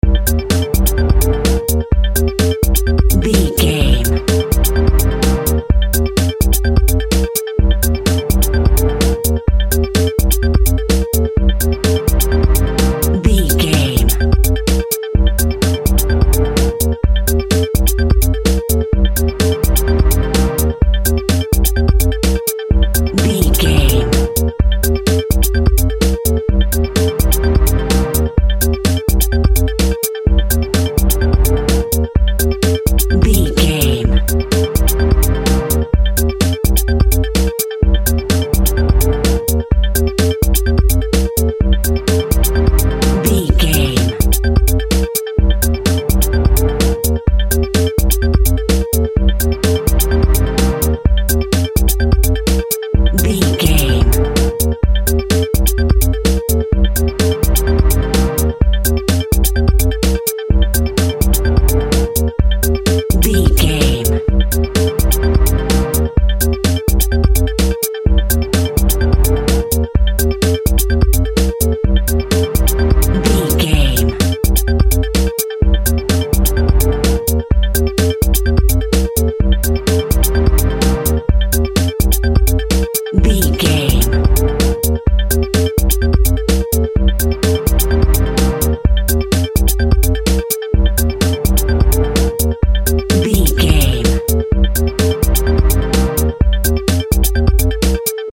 Fast paced
Aeolian/Minor
dark
futuristic
driving
energetic
synthesiser
drum machine
strings
Drum and bass
break beat
sub bass
synth lead